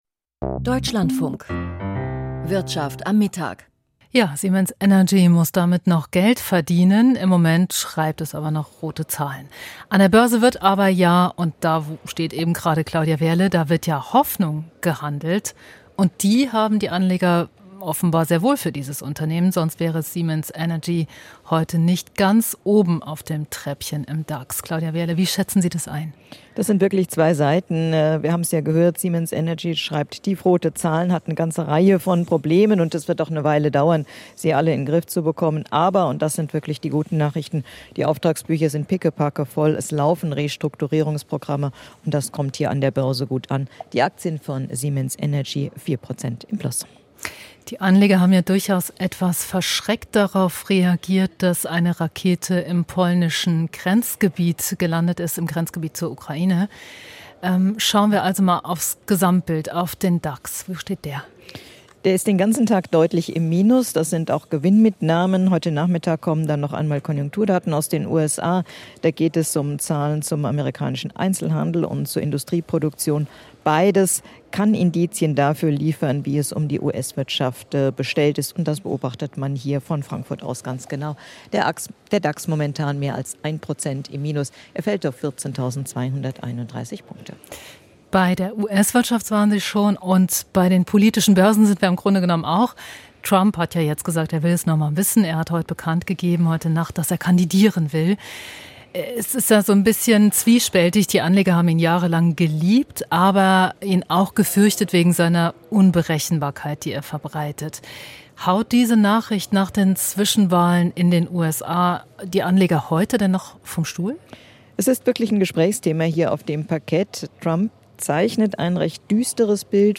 Börsengespräch